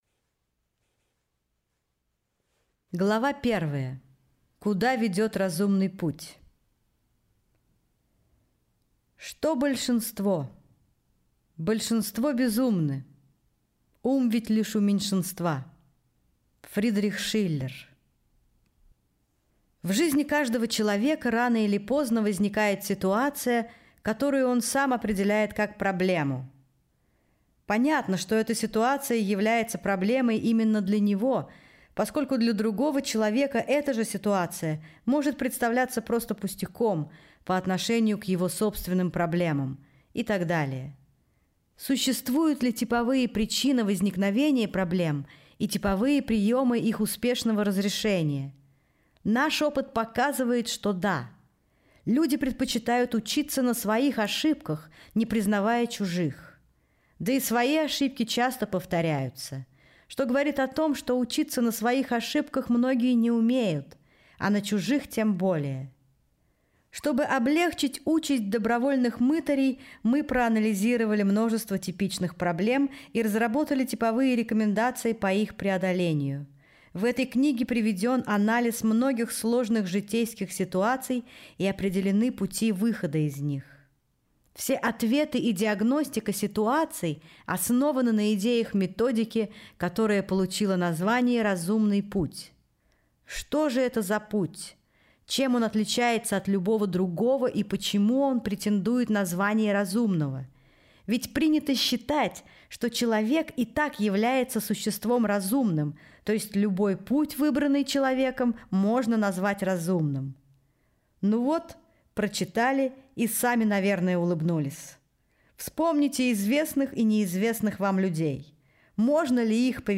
Аудиокнига Уроки судьбы в вопросах и ответах | Библиотека аудиокниг